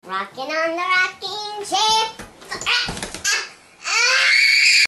RoCkING CHAIR Sound Effects Free Download